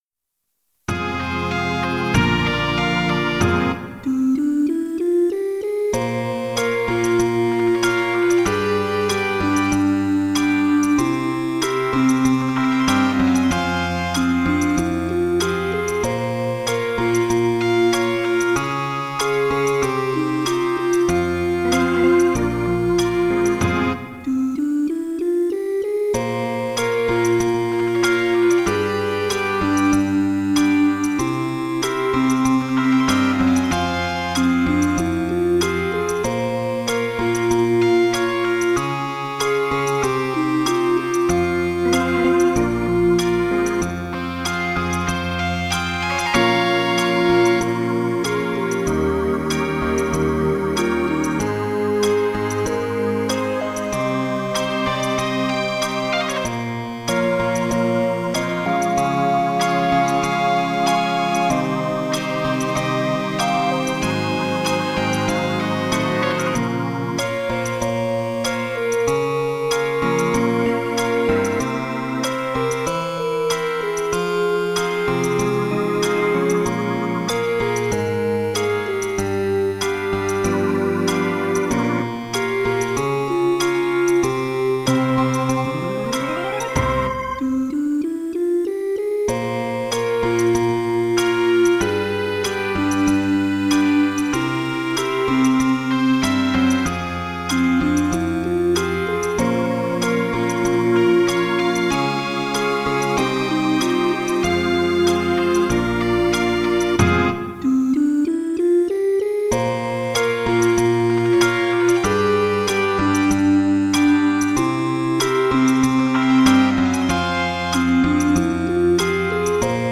ЗВУКОВЫЕ ИЛЛЮСТРАЦИИ НОТ
НЕСКОЛЬКО МЕЛОДИЙ ВЕЧЕРНЕГО НАСТРОЕНИЯ.